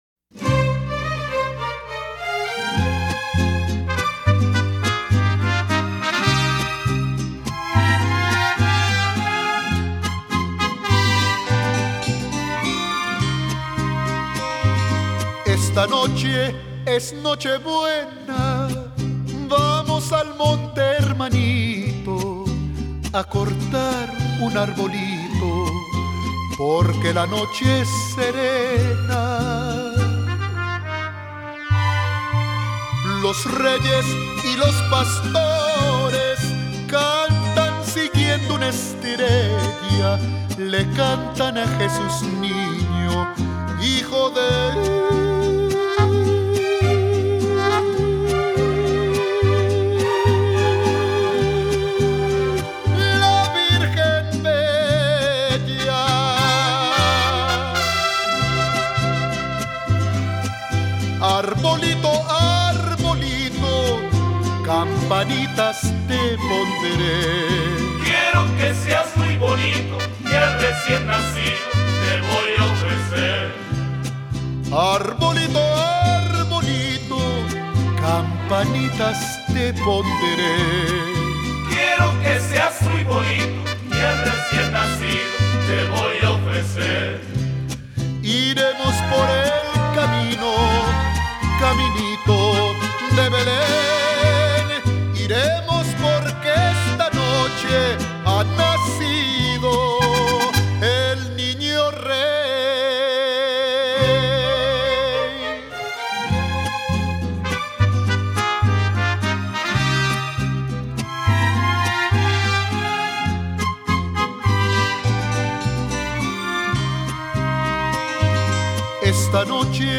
Latin-American Villancico Ranchero